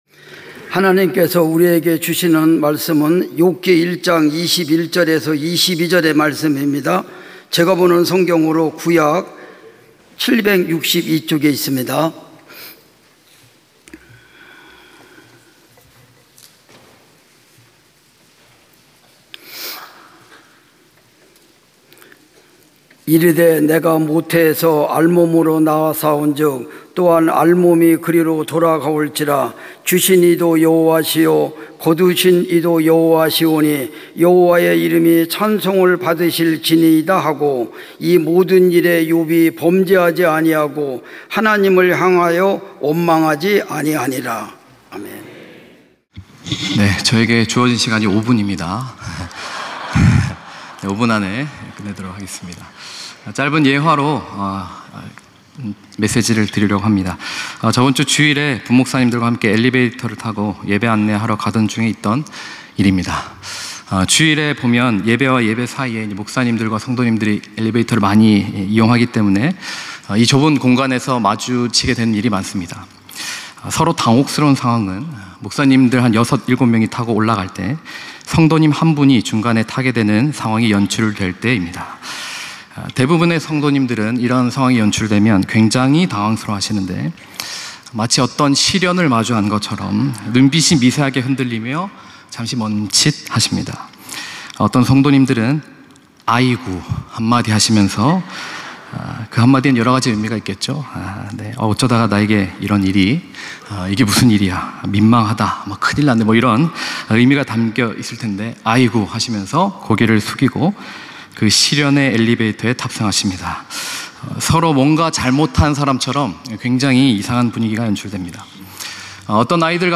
찬양예배 - 능동태 감사